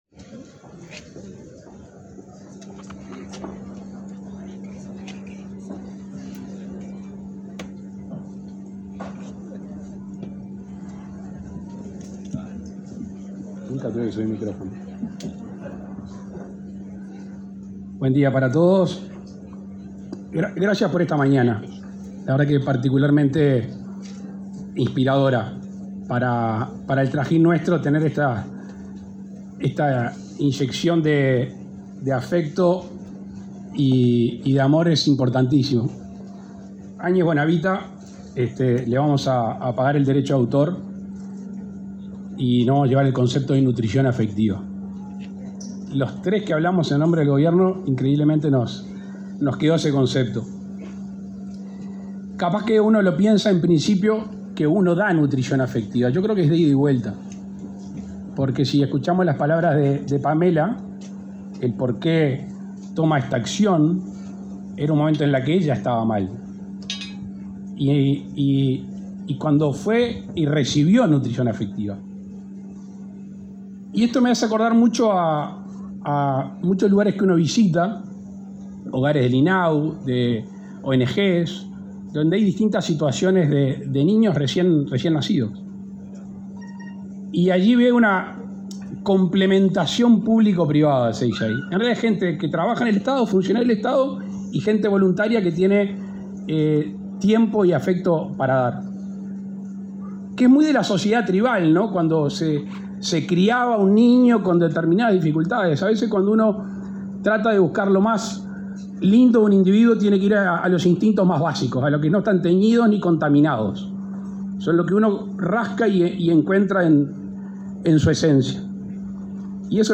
Palabras del presidente Luis Lacalle Pou
Este martes 10, el presidente de la República, Luis Lacalle Pou, participó en la inauguración de las obras de ampliación de una sala de la Fundación